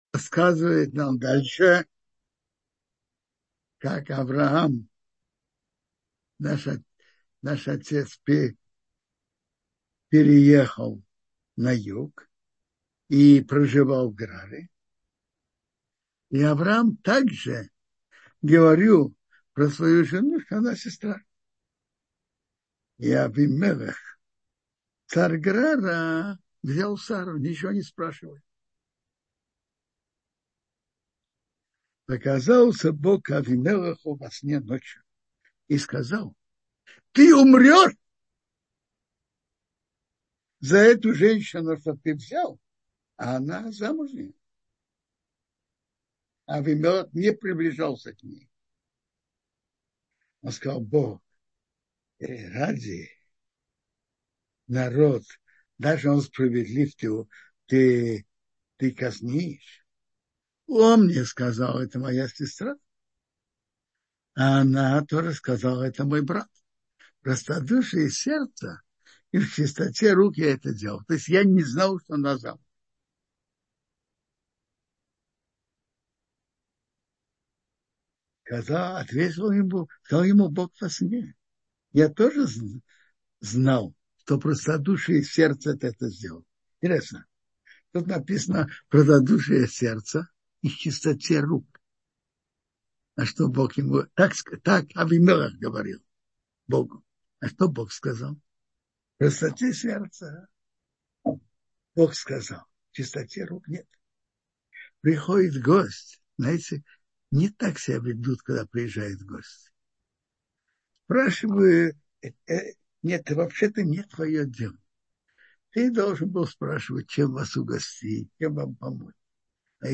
— слушать лекции раввинов онлайн | Еврейские аудиоуроки по теме «Недельная глава» на Толдот.ру